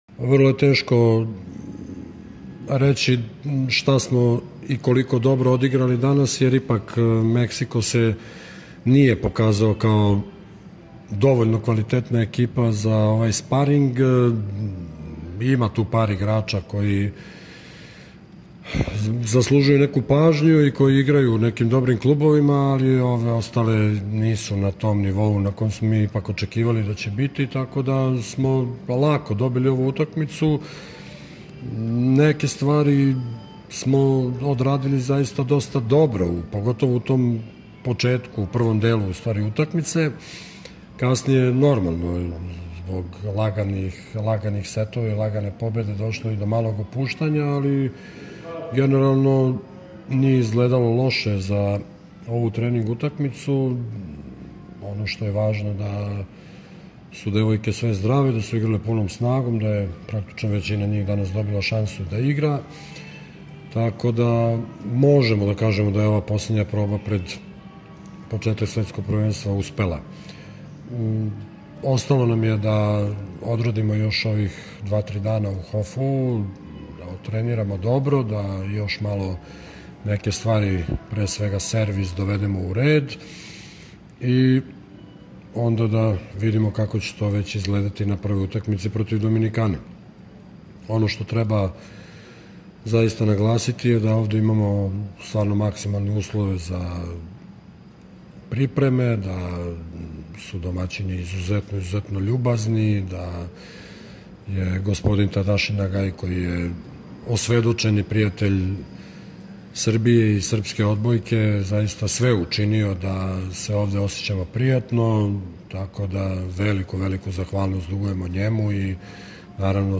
Izjava Zorana Terzića